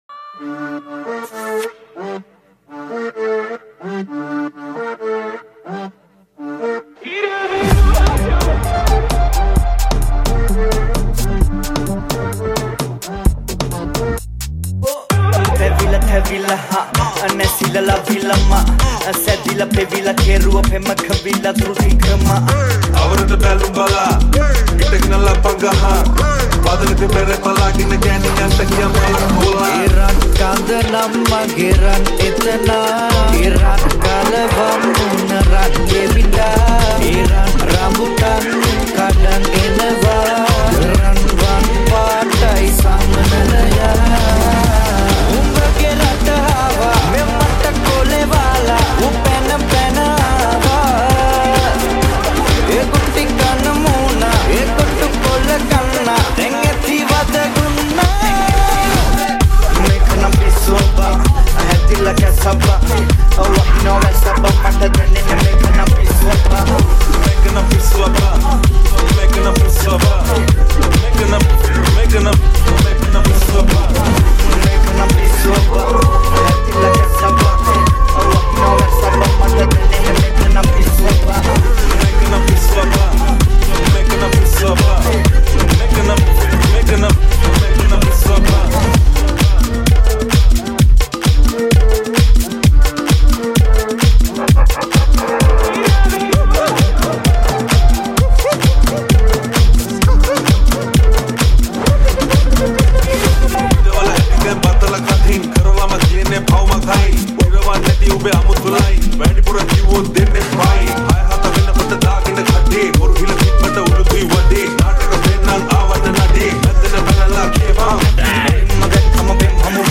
High quality Sri Lankan remix MP3 (7.6).